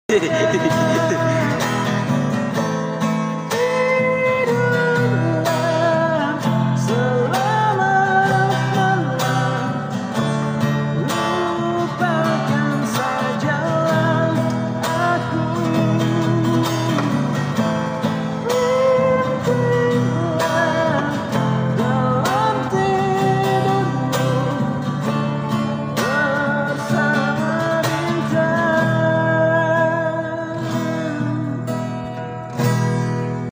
suara nya enak bang bikin merinding saya
keren bosku suaranya